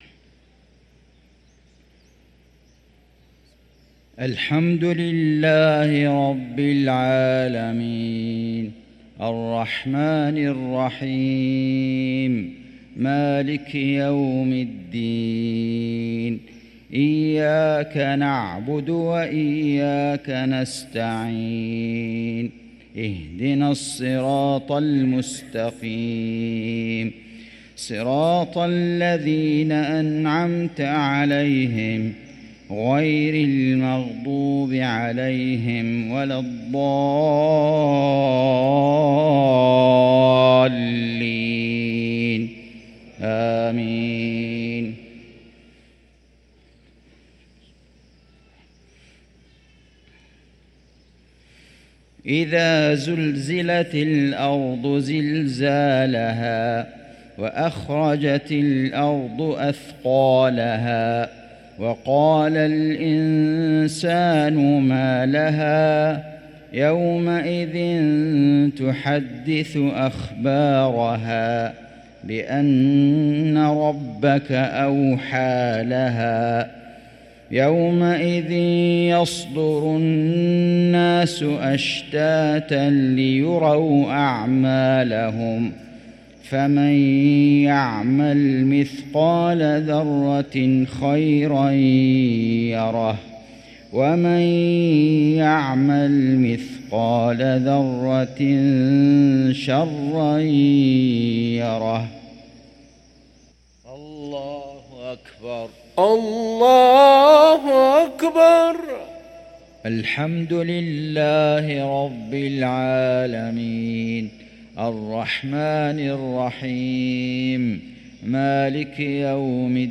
صلاة المغرب للقارئ فيصل غزاوي 18 جمادي الآخر 1445 هـ
تِلَاوَات الْحَرَمَيْن .